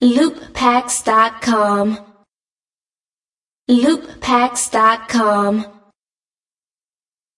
快节奏的鼓点循环
描述：听起来与通常的嘻哈鼓循环有点不同
标签： 180 bpm Hip Hop Loops Drum Loops 1.79 MB wav Key : Unknown FL Studio
声道立体声